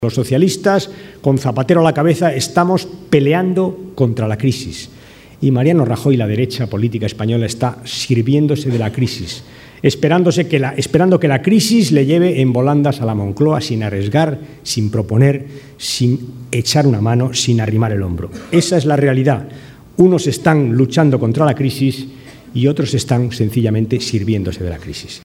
Denuncia, en un acto público en Ciudad Real, que “algún gurú de la demoscopia política” le ha dicho a Rajoy “no te pringues, esto de la crisis que lo paguen los socialistas”